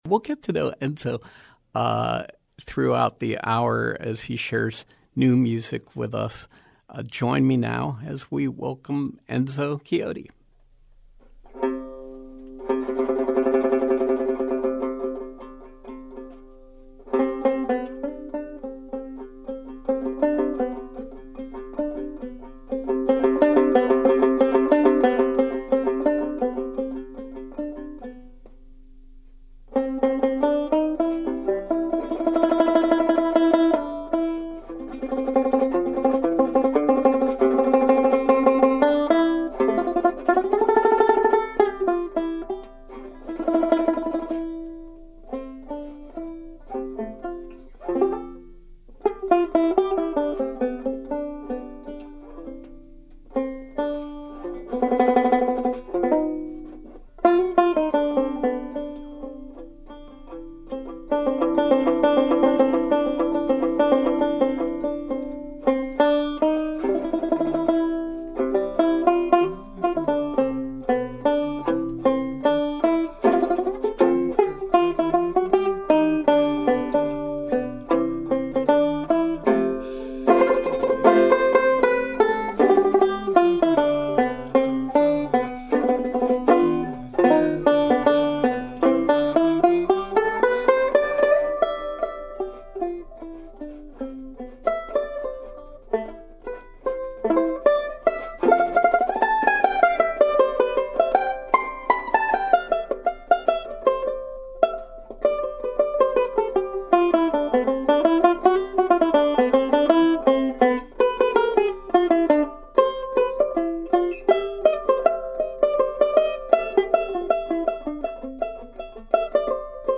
Live Music
classical and modern jazz works on banjo